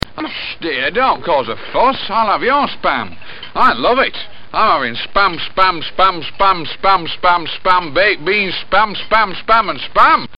Tags: Media Infinitum Absurdum Repetitious Humor Experiment Funny Repeated words